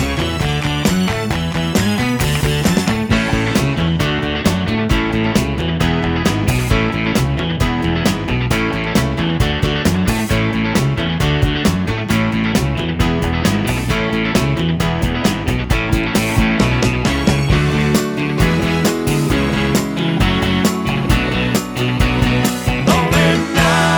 no Backing Vocals Soul / Motown 2:37 Buy £1.50